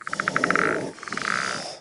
step2.ogg